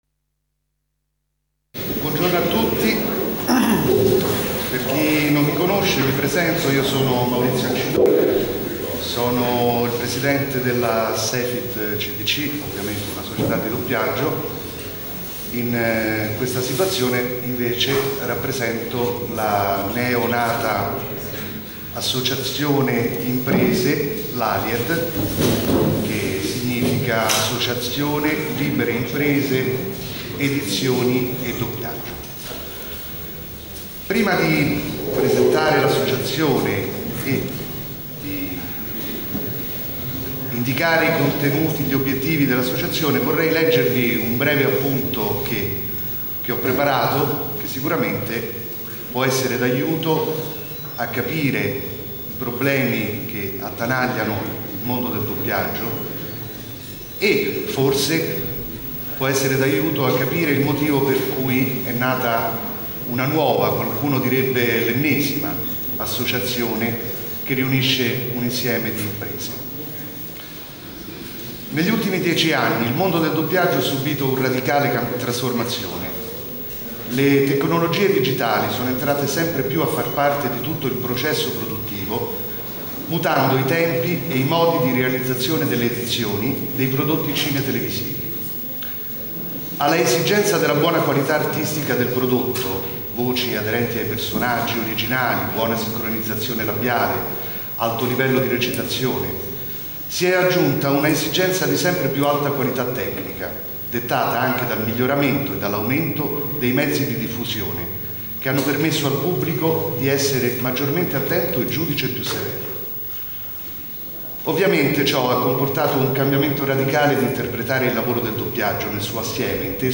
Giornata di studi della IV edizione del Premio Nazionale di Doppiaggio